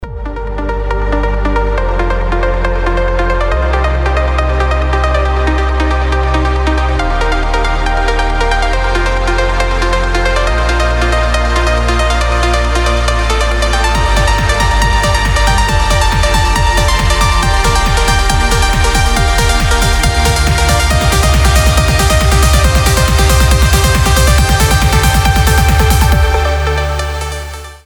• Качество: 320, Stereo
Electronic
красивая мелодия
нарастающие
Trance
melodic trance
Приятный и мелодичный транс.